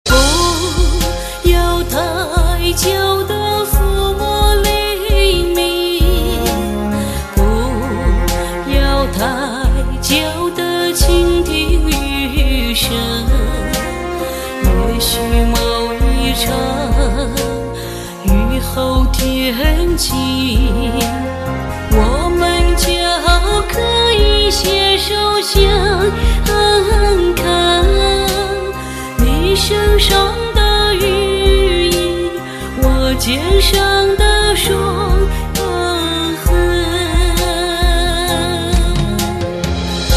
M4R铃声, MP3铃声, 华语歌曲 72 首发日期：2018-05-14 09:48 星期一